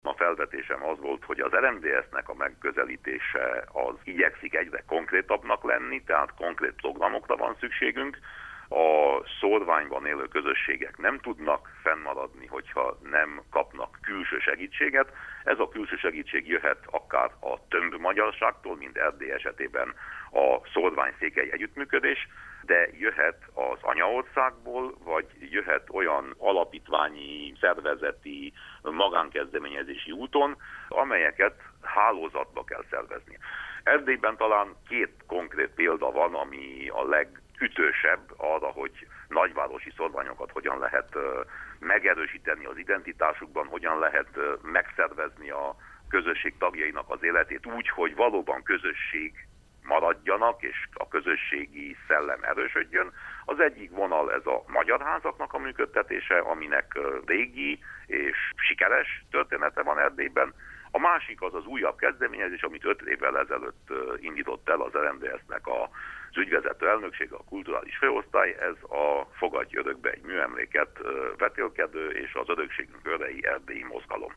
A nagyvárosi szórványról tárgyaltak tegnap Budapesten a Kárpát-medencei Magyar Képviselők Fóruma szórvány-diaszpóra munkacsoportjának éves ülésén. A tanácskozáson részt vett Winkler Gyula, az RMDSZ európai parlamenti képviselője is, akit a megbeszélésről kérdeztünk.